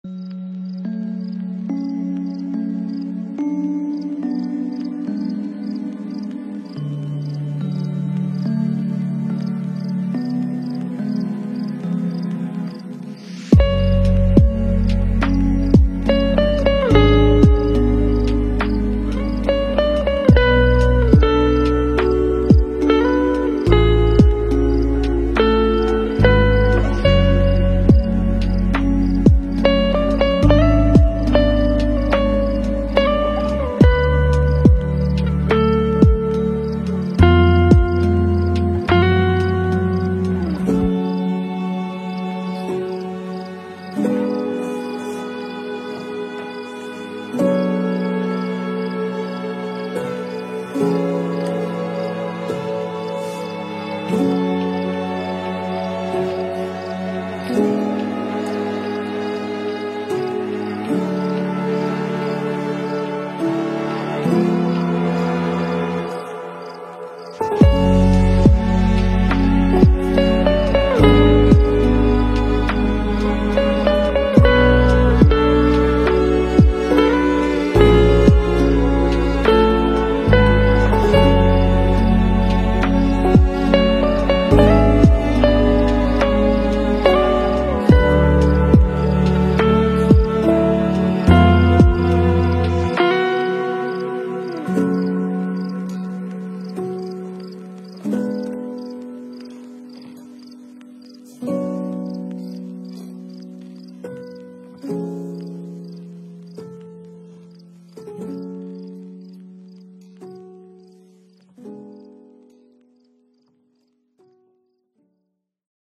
Мы подобрали для вас лучшие lo-fi песни без слов.
Грустная мелодия 🎧